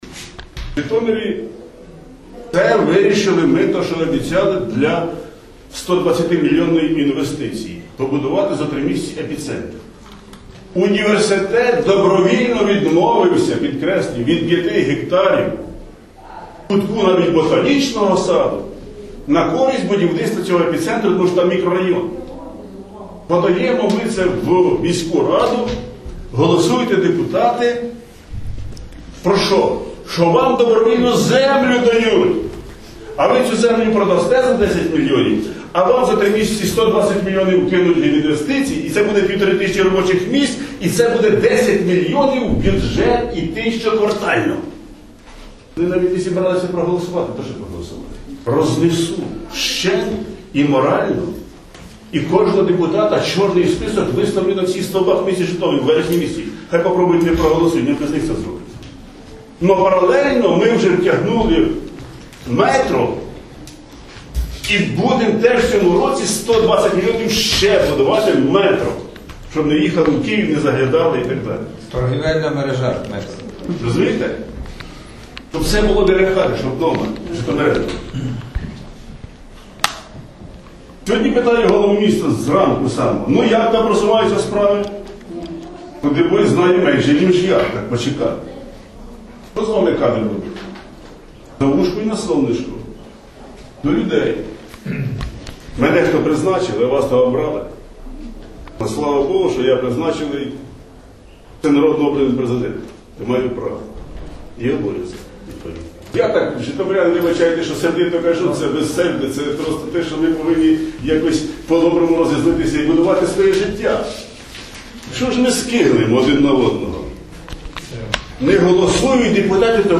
Глава Житомирской ОГА Сергей Рыжук выступил с заявлением, в котором открыто пригрозил депутатам горсовета репрессиями, если те не проголосуют за вопрос передачи земли сети супермаркетов «Эпицентр».